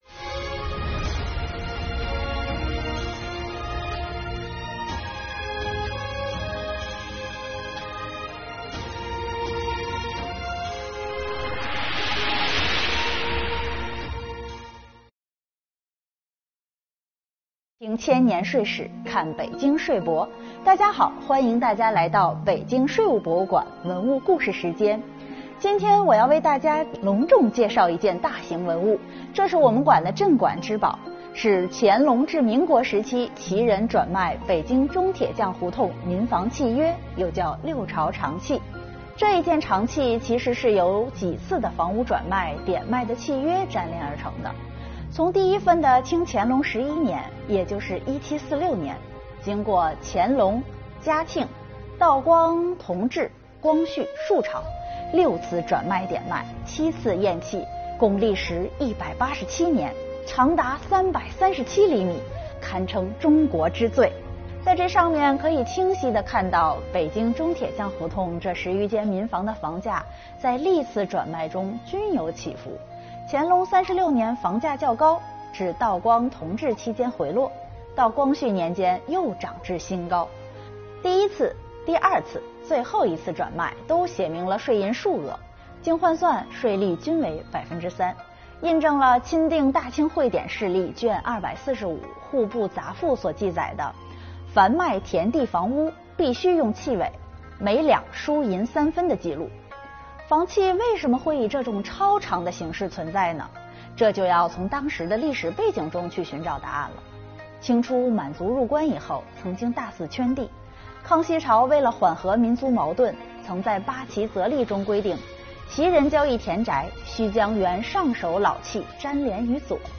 北京税务博物馆文物故事讲述人为大家介绍六朝长契背后的故事↓